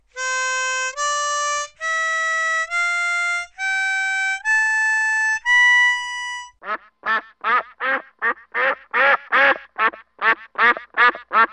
Instead of playing clear notes, one reed sounds a bit strange.
Blow hole 4 made a beautiful quack!
Duck-Harp-z.mp3